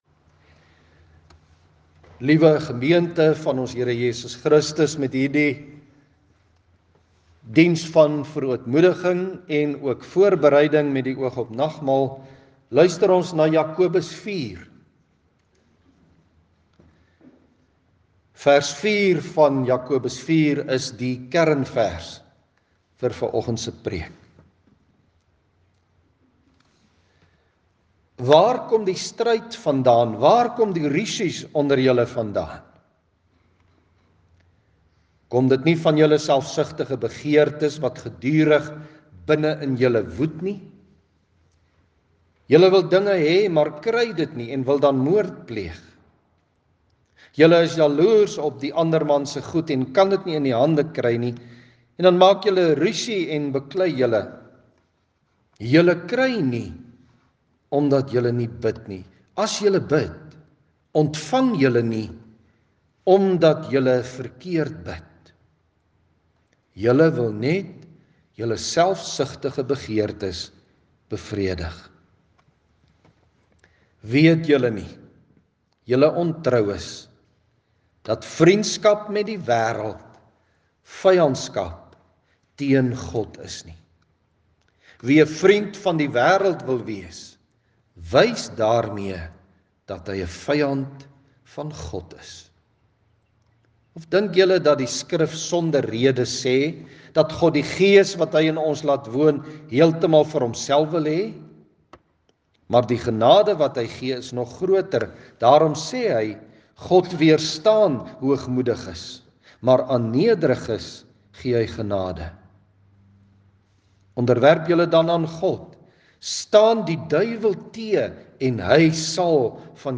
Klankbaan
Sang:Voor die erediens met die oog op verootmoediging: Ps.36:1,2,3 en Ps.69:1,3,12